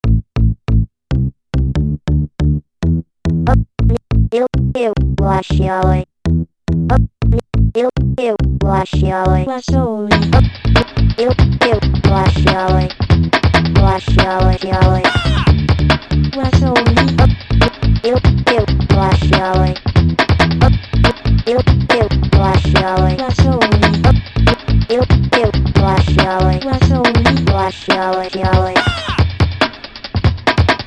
ちょっとJazzy。